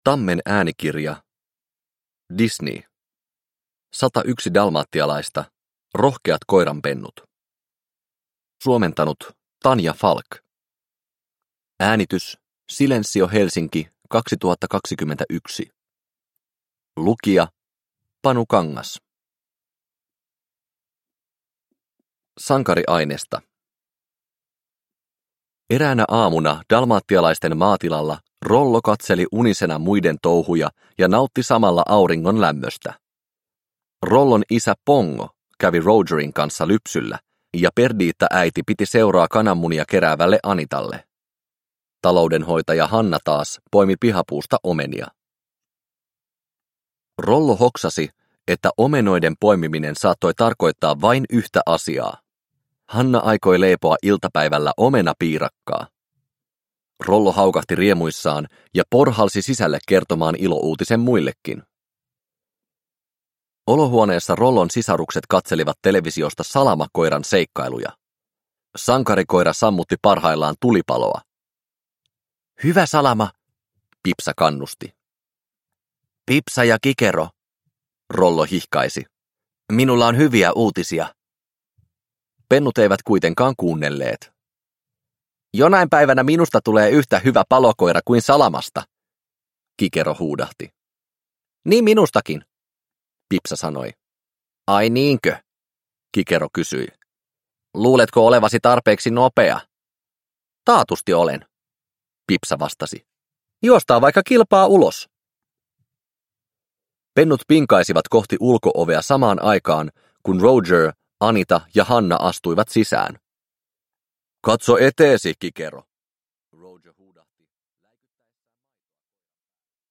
Disney. 101 dalmatialaista. Rohkeat koiranpennut – Ljudbok – Laddas ner